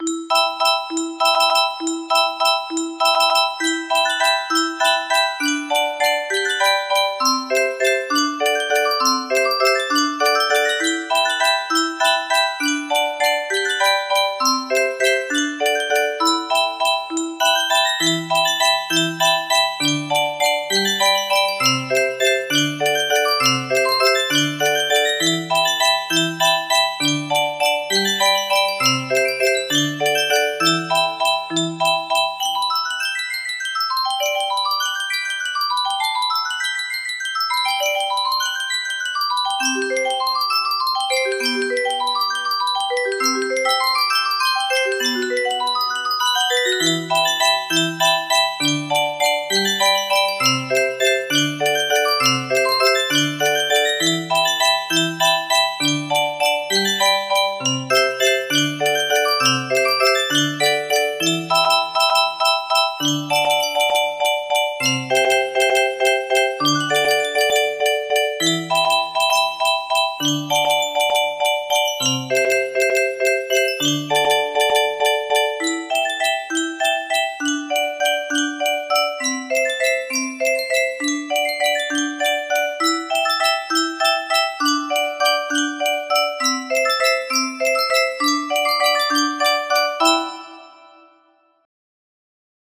Full range 60
Imported from midi